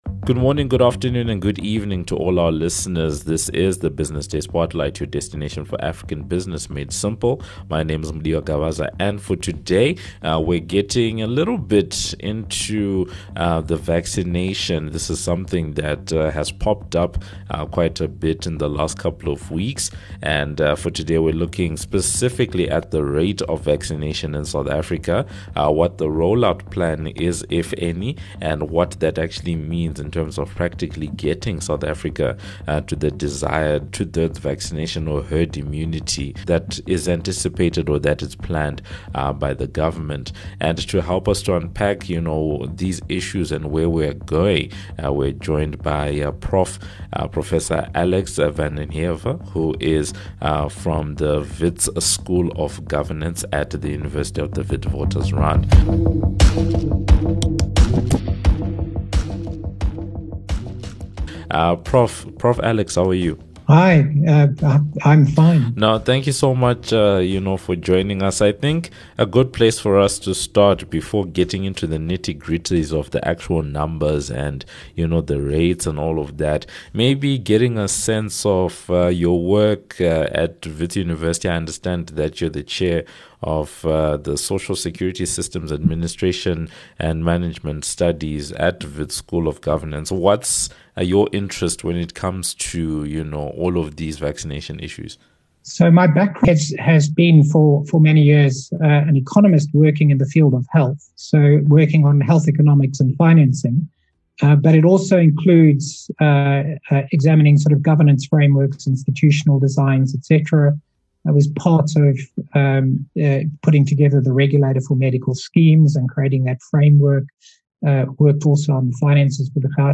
The discussion focuses on the state of vaccinations in SA, the economics of procuring doses, difference in rollout between developed and developing countries and the potential of a third wave of infection in SA.